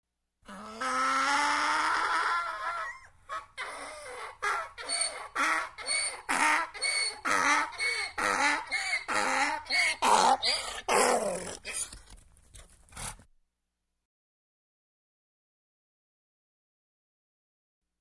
Домашние животные звуки скачать, слушать онлайн ✔в хорошем качестве